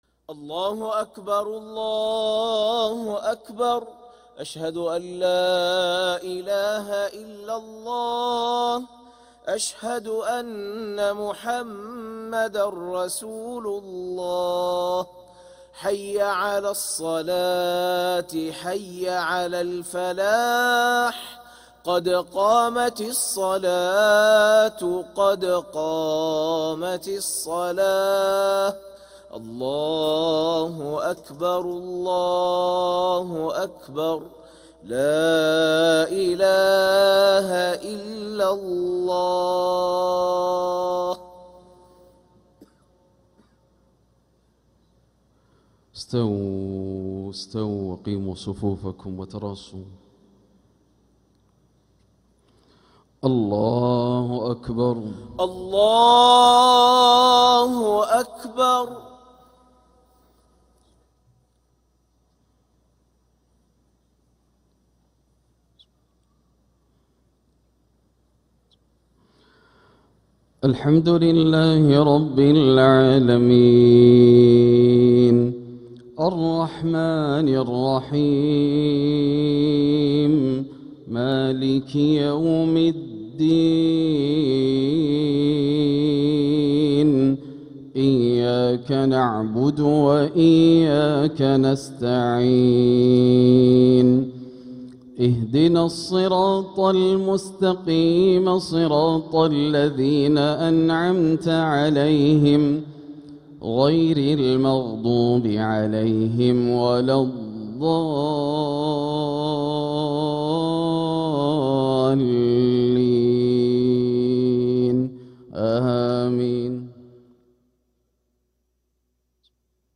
Makkah Fajr - 13th April 2026